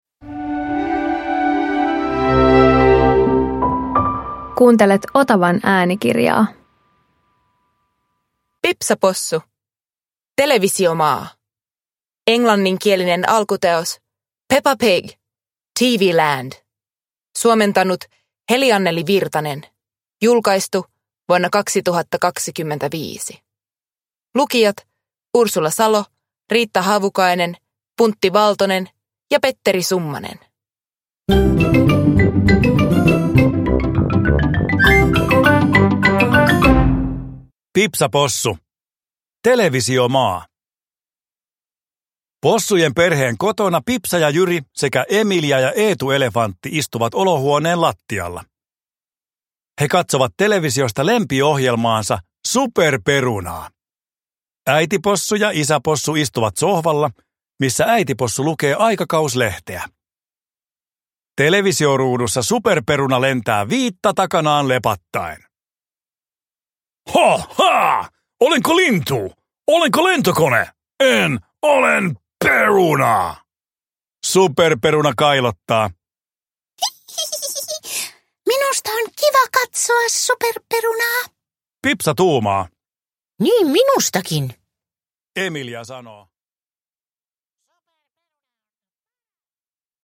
Pipsa Possu - Televisiomaa – Ljudbok